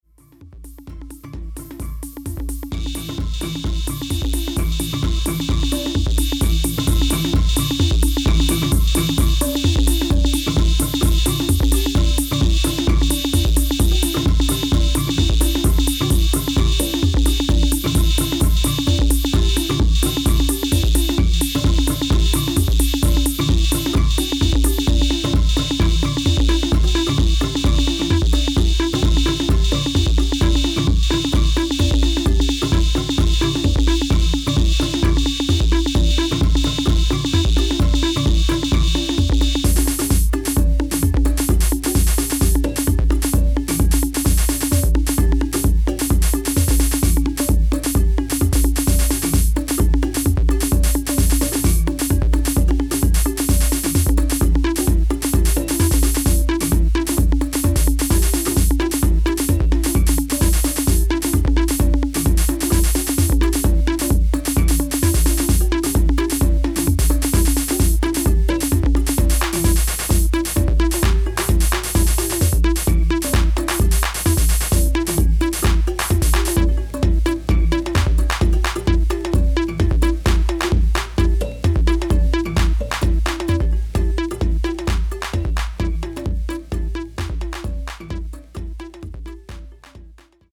modern percussion edits